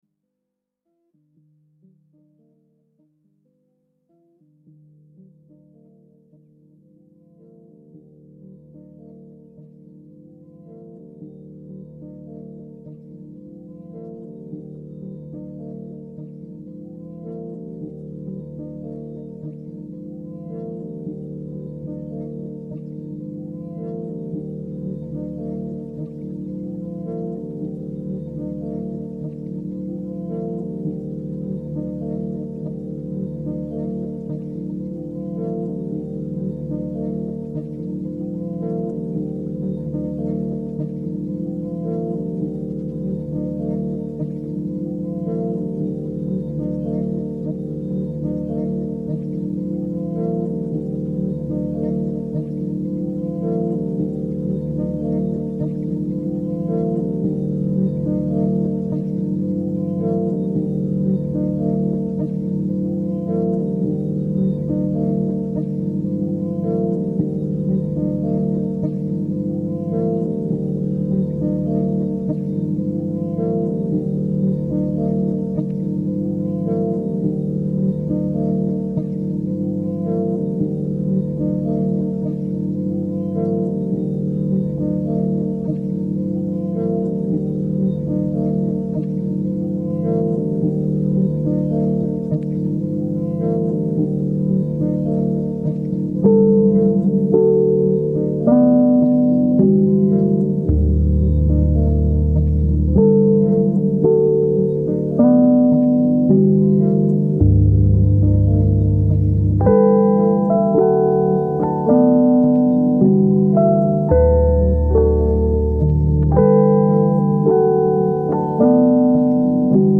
Ambient instrumental background song.
Genres: Relaxing Music
Tempo: slow